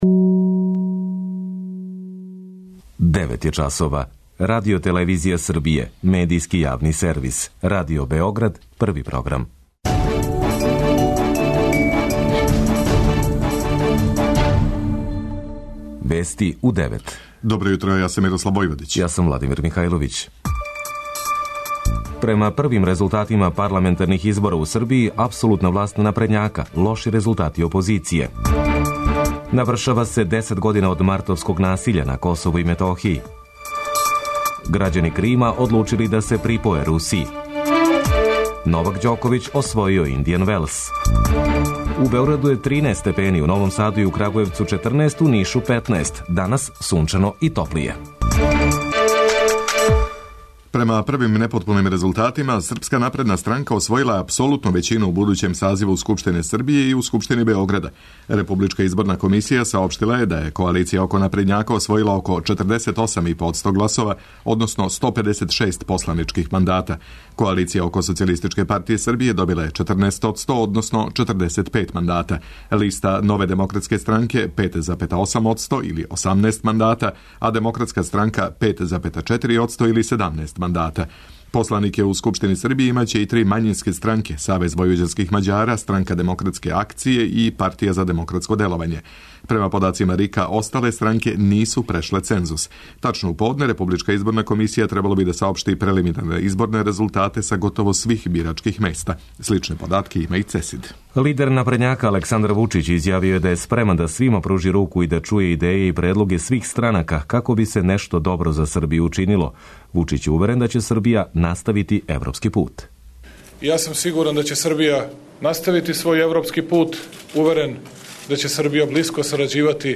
Уредници и водитељи
преузми : 9.96 MB Вести у 9 Autor: разни аутори Преглед најважнијиx информација из земље из света.